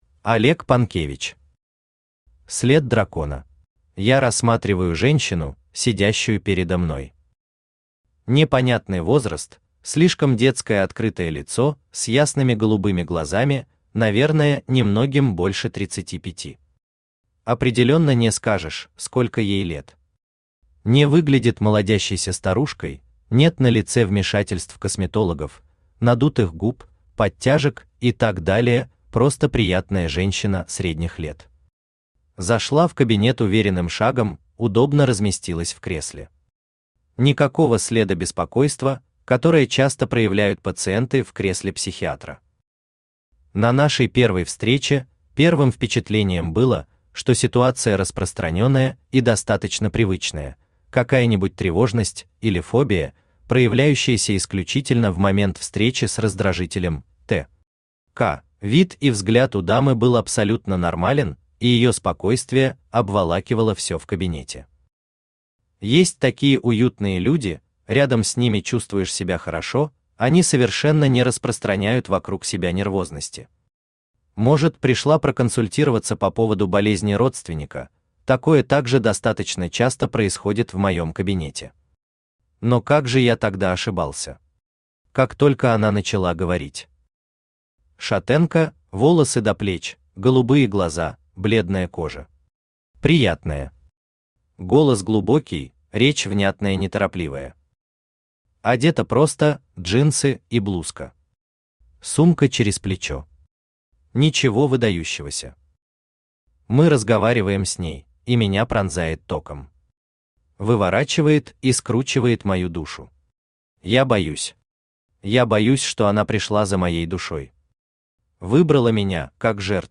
Аудиокнига Свет Дракона | Библиотека аудиокниг
Aудиокнига Свет Дракона Автор Олег Панкевич Читает аудиокнигу Авточтец ЛитРес.